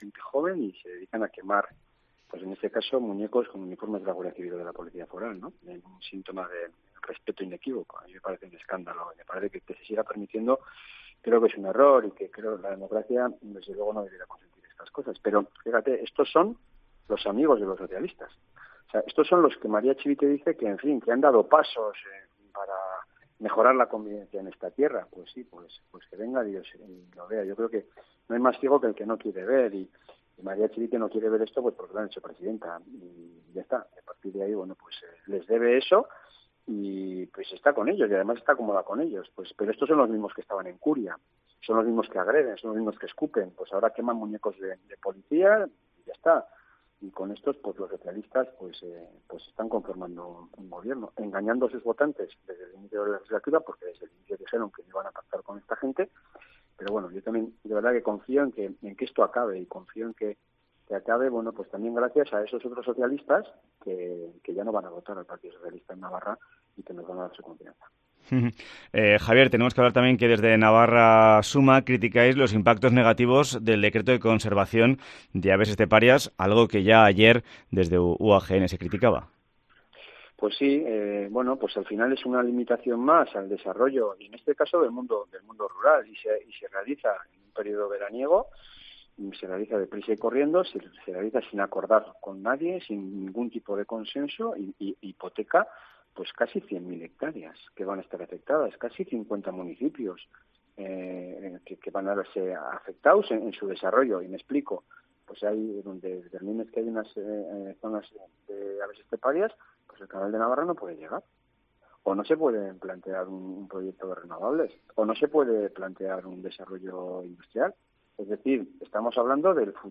Entrevista con Javier Esparza en COPE Navarra